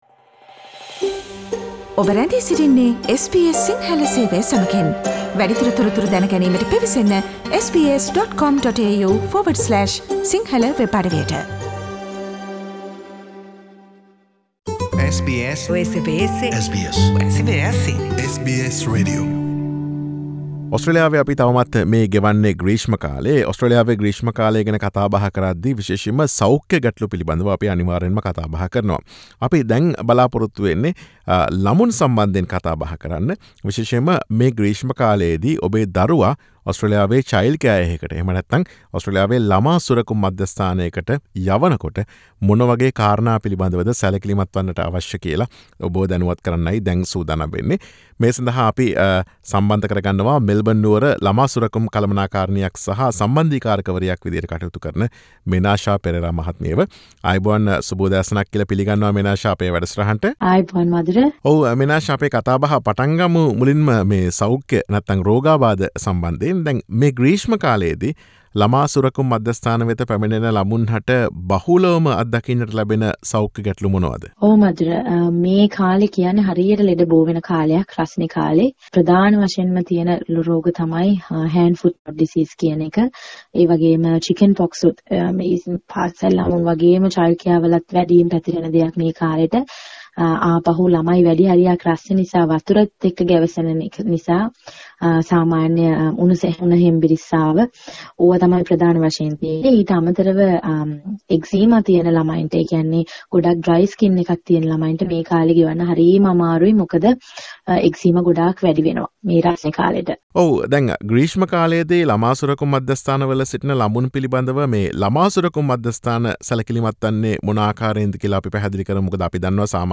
SBS සිංහල සේවය සිදු කළ සාකච්ඡාව.